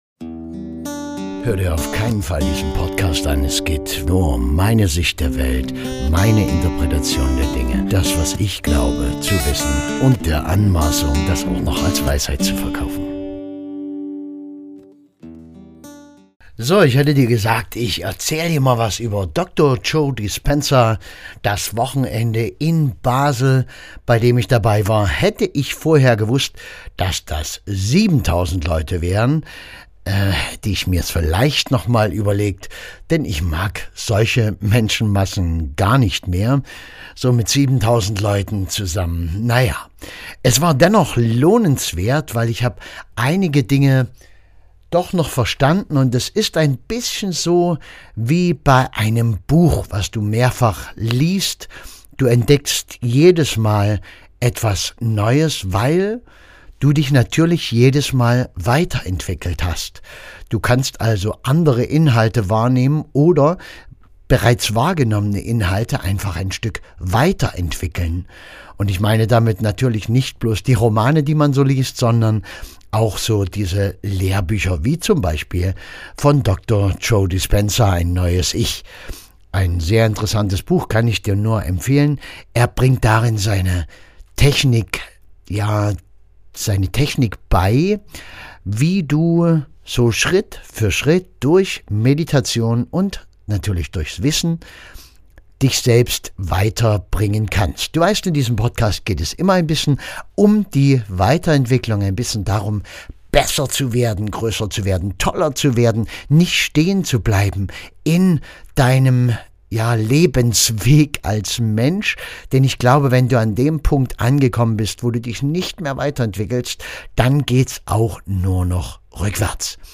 Live in Basel